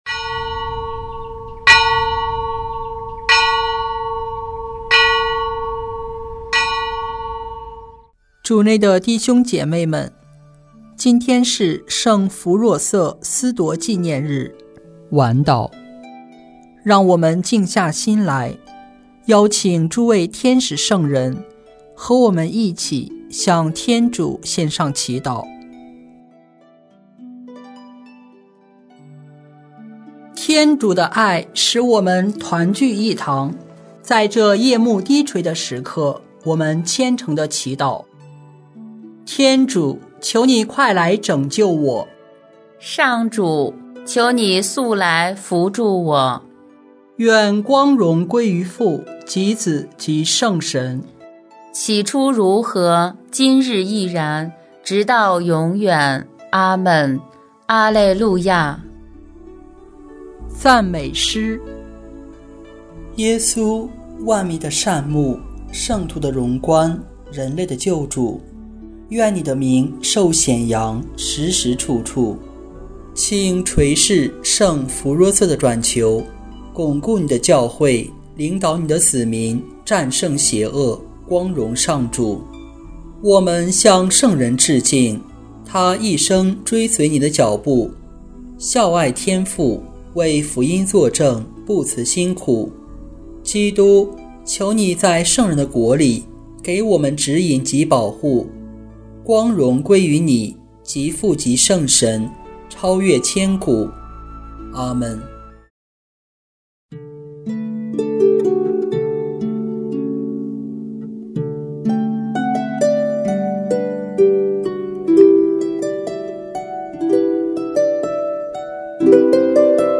圣咏吟唱 圣咏 131 天主给达味宗室的恩许 “上主天主要把耶稣祖先达味的御座赐给他。”